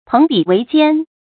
注音：ㄆㄥˊ ㄅㄧˇ ㄨㄟˊ ㄐㄧㄢ
朋比為奸的讀法